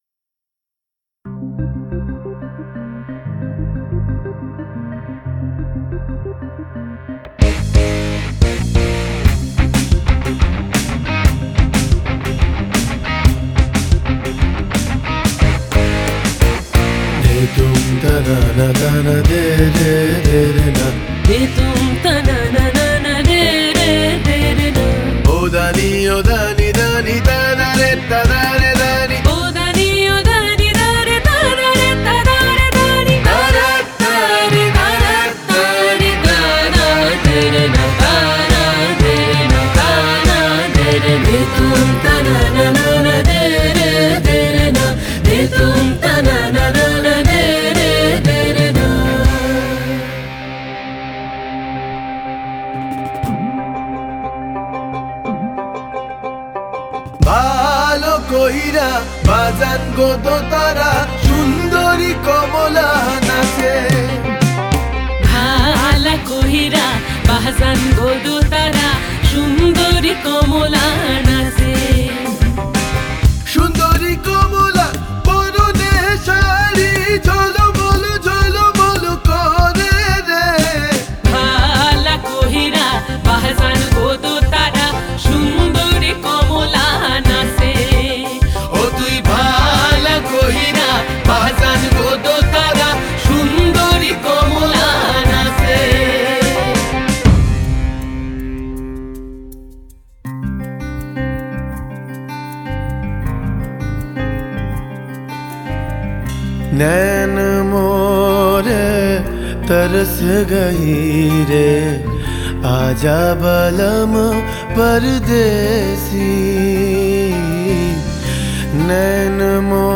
Songs Mp3 Bengali > Folk In Films